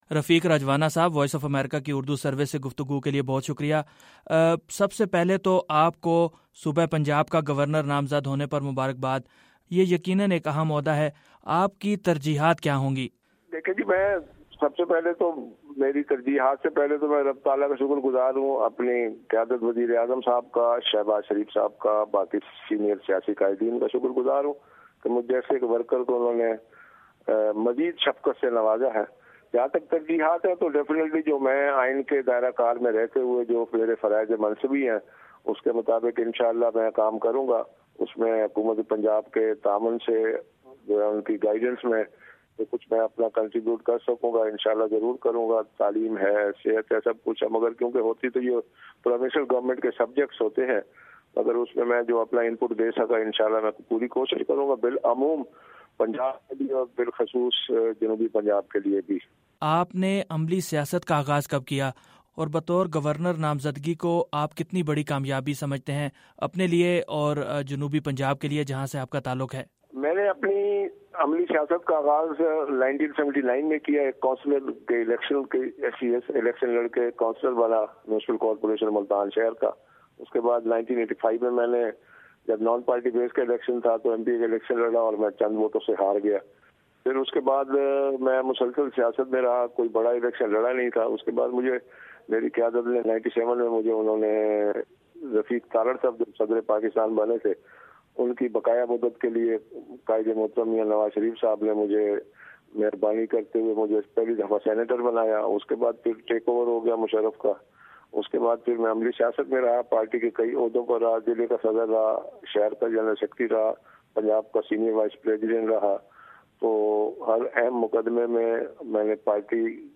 رفیق رجوانہ کا وائس آف امریکہ سے خصوصی انٹرویو